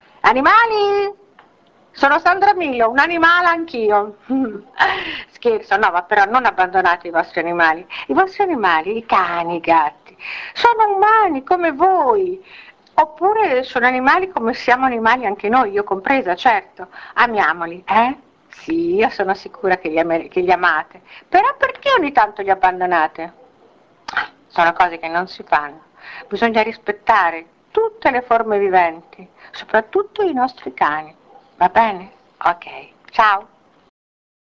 ASCOLTA GLI SPOT DI SANDRA MILO